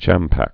(chămpăk, chŭmpŭk) or cham·pa·ca (chămpə-kə, chŭm-)